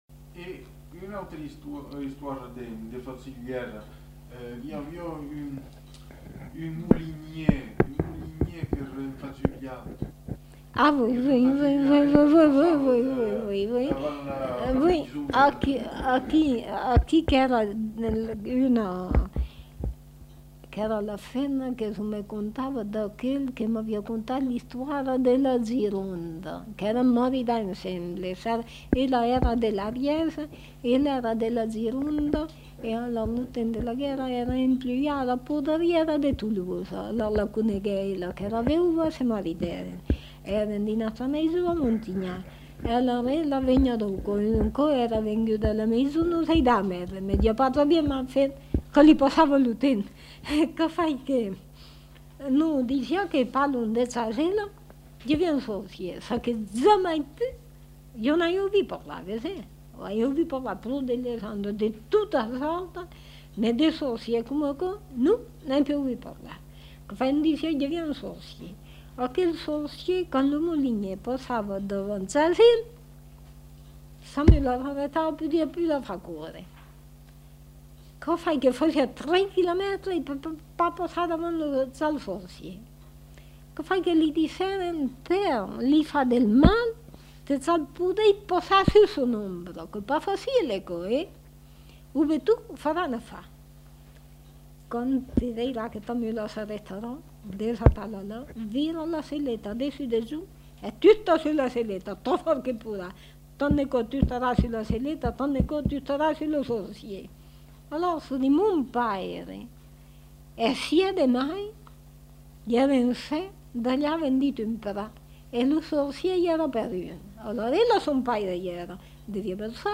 Aire culturelle : Périgord
Lieu : La Chapelle-Aubareil
Genre : conte-légende-récit
Type de voix : voix de femme
Production du son : parlé